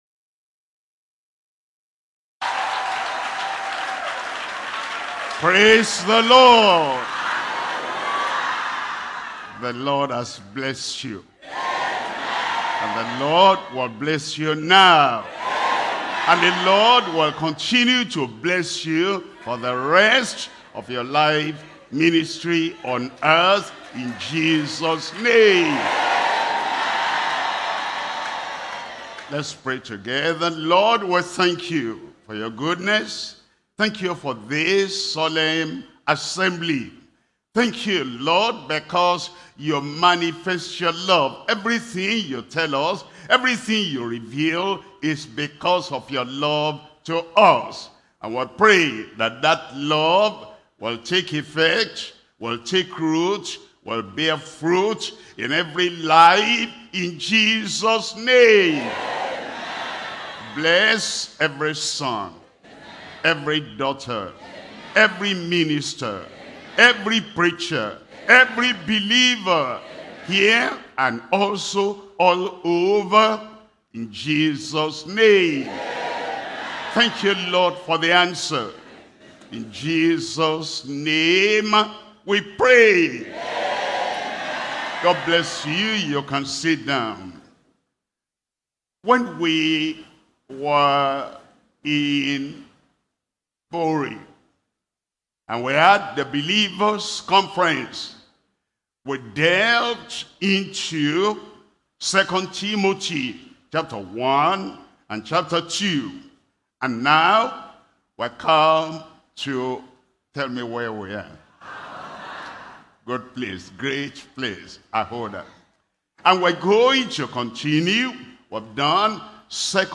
Sermons – Deeper Christian Life Ministry, United Kingdom